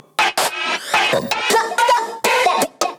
这些循环专为地下电子音乐打造，容量为 145 MB，包含 24 位 WAV 格式、160 BPM 的音频文件，可直接导入你的项目。
• 160 BPM